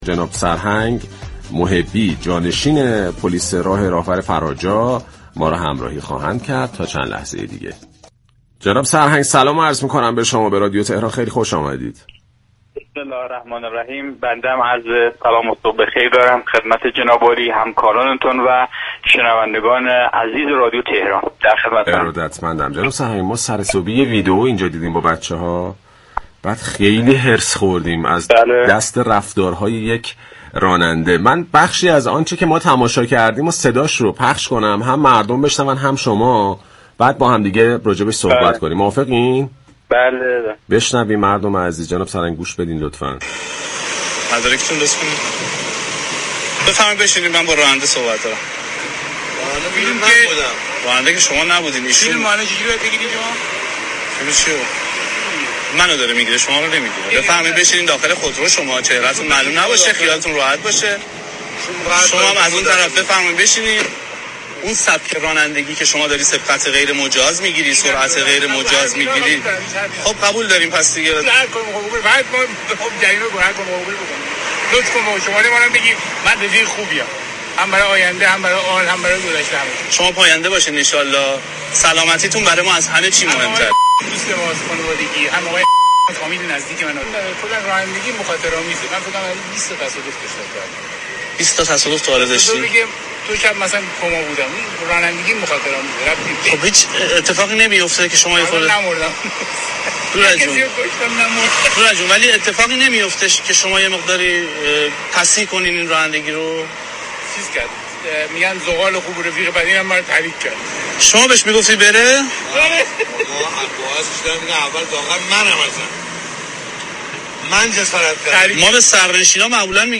به گزارش پایگاه اطلاع رسانی رادیو تهران، سرهنگ سیاوش محبی جانشین رئیس پلیس راهور فراجا در گفت و گو با «شهر آفتاب» اظهار داشت: نرخ جرائم رانندگی فعلی در كشورمان بازدارنده نیست.